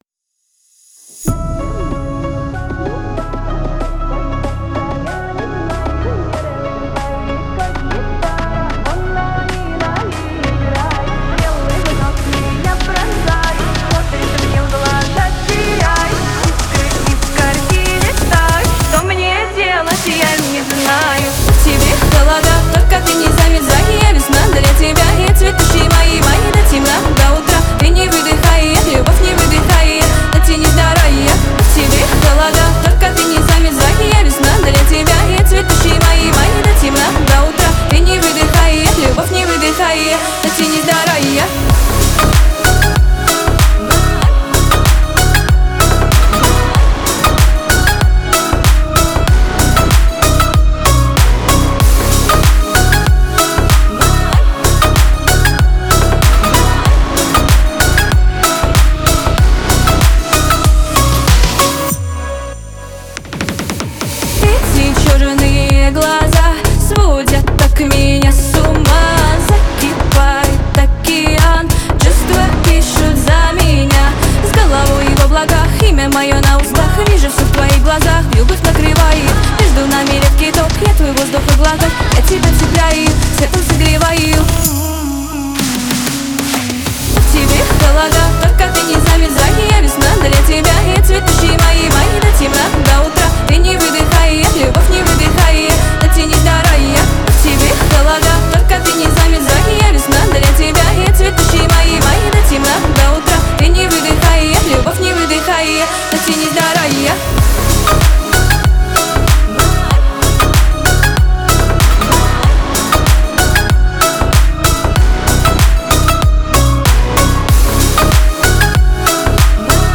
танцевальные песни